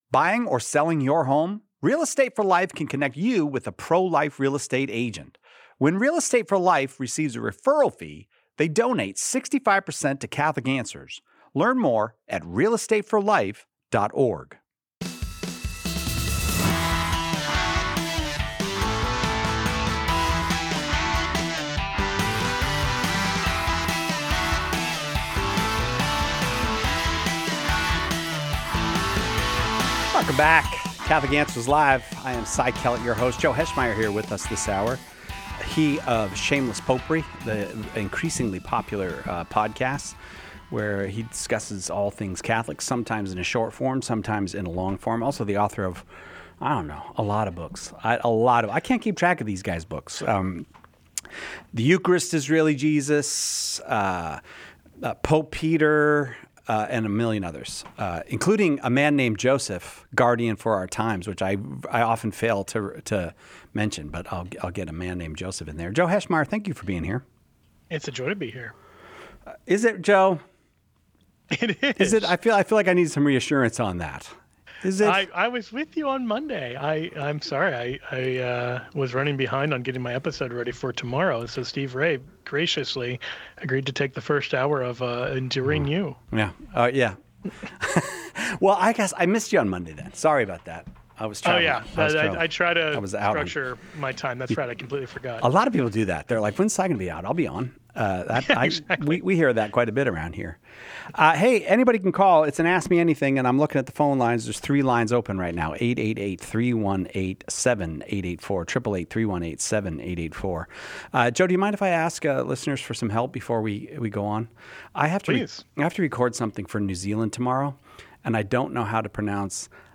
In this episode of Catholic Answers Live , listeners ask insightful questions on a variety of Catholic topics. Discussion includes the history and value of the Liturgy of the Hours for laypeople, Church teaching on keeping cremated ashes at home, and how miracles are evaluated in the canonization process when multiple saints are invoked. Other questions address the origin of John the Baptist’s baptism, the theological distinction between infant baptism and Old Covenant circumcision, and whether someone who doesn’t attend Mass may receive Communion during a hospice visit.